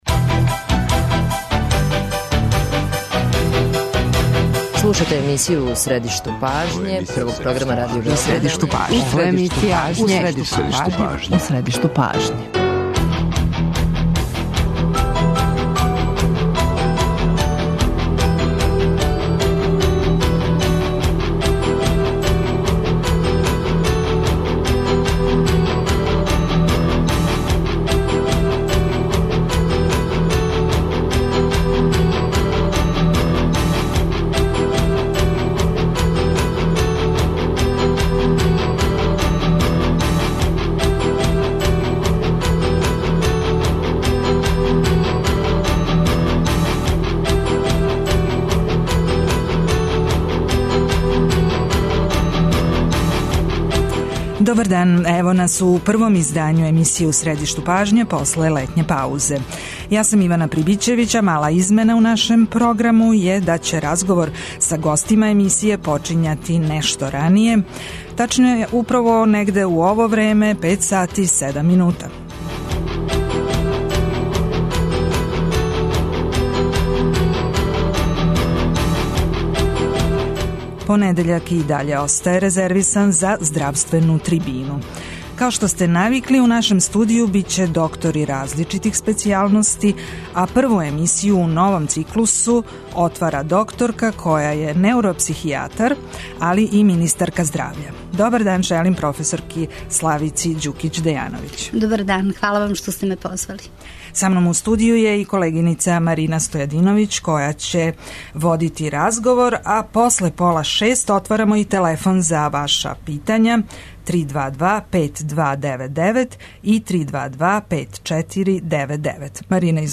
Гошћа Здравствене трибине је министарка здравља Славица Ђукић Дејановић.